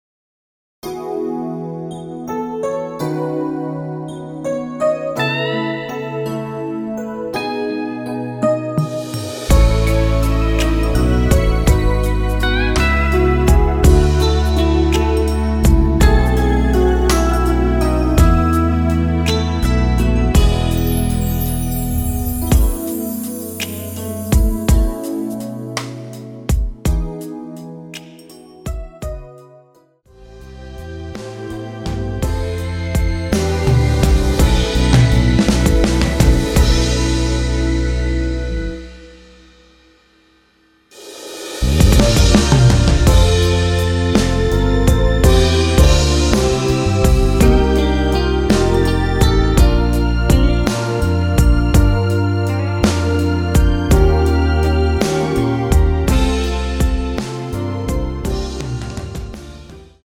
원키에서(+2)올린 MR입니다.
◈ 곡명 옆 (-1)은 반음 내림, (+1)은 반음 올림 입니다.
앞부분30초, 뒷부분30초씩 편집해서 올려 드리고 있습니다.
중간에 음이 끈어지고 다시 나오는 이유는